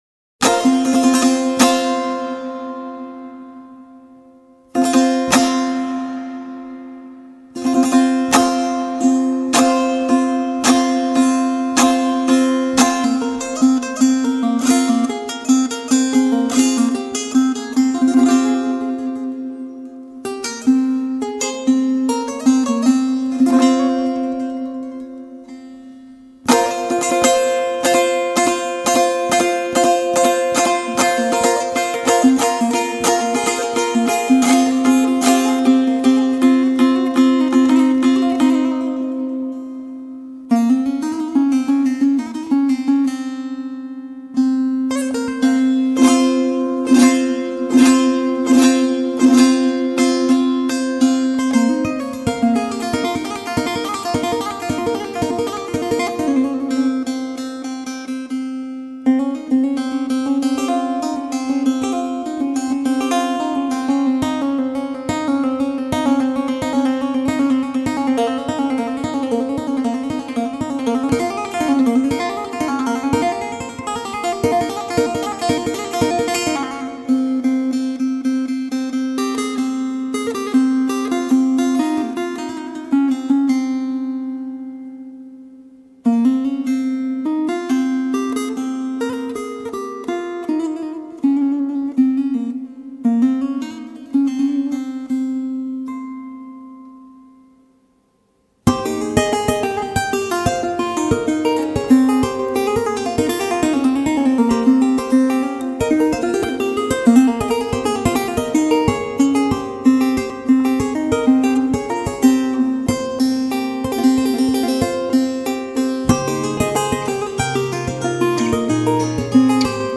آهنگ لایت زیبا و دلنشین با نوای زیبای ترکیه ای
اثری دلنشین و آرام
[نوع آهنگ: لایت]